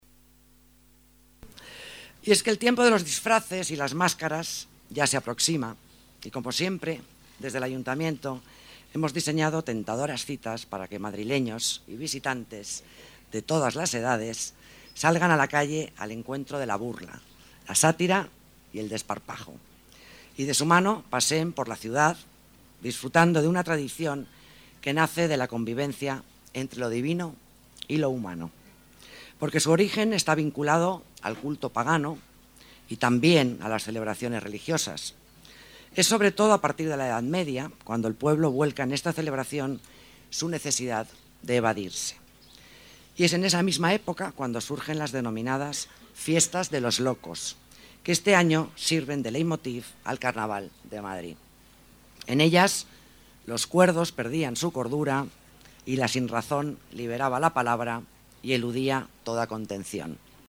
Nueva ventana:Palabras de la delegada de Las Artes,Alicia Moreno, durante la presentación del Carnaval 2011